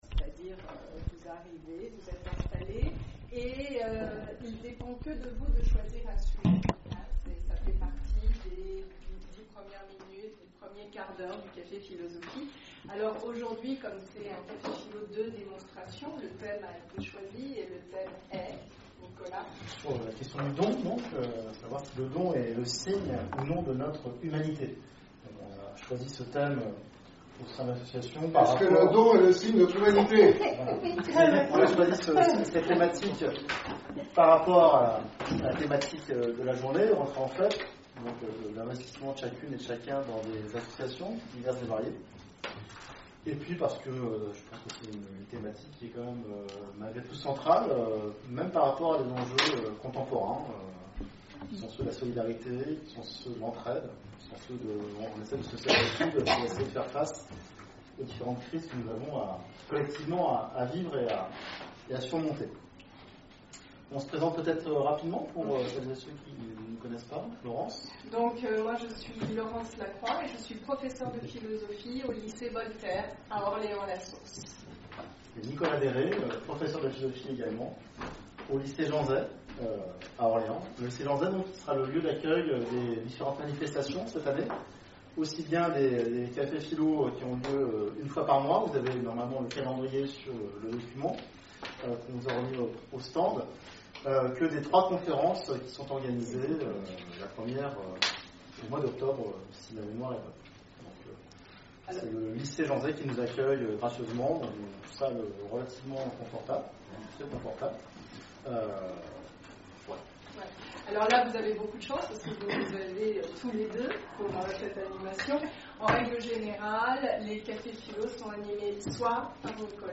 Conférences et cafés-philo, Orléans
CAFÉ-PHILO PHILOMANIA Le don est-il le signe de notre humanité ?